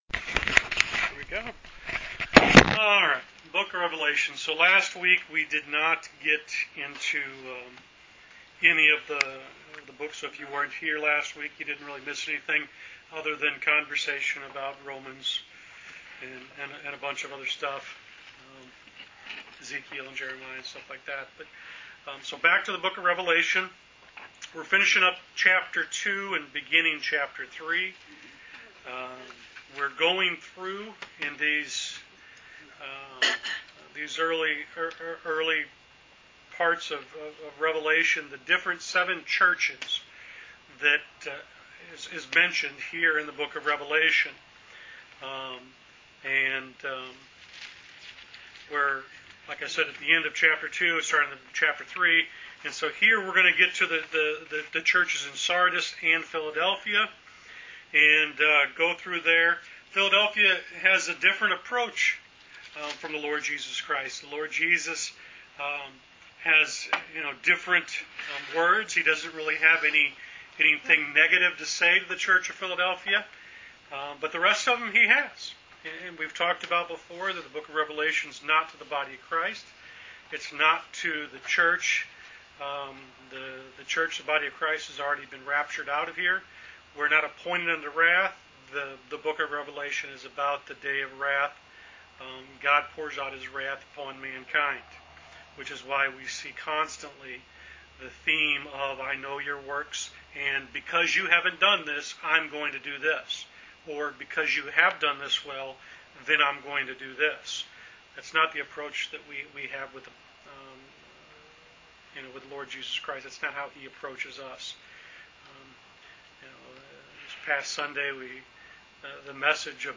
Wednesday Bible Study: Rev Ch 3